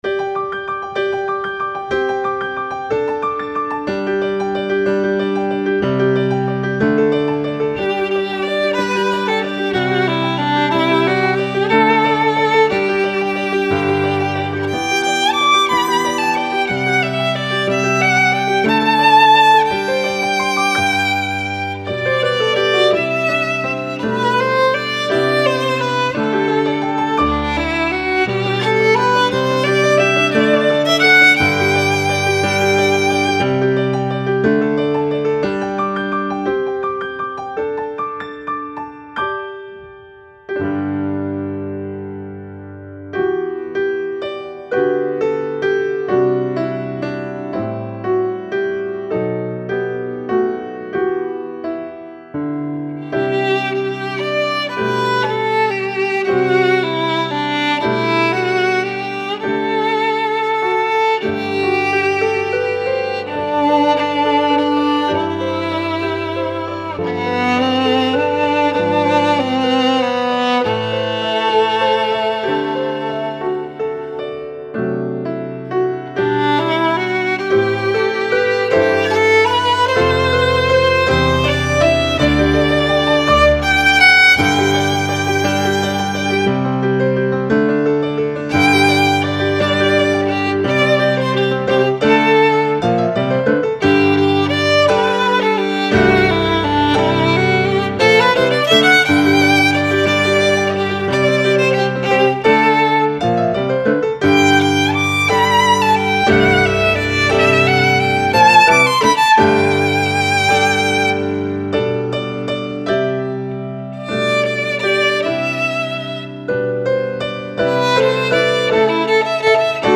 Intermediate Violin Solos for Thanksgiving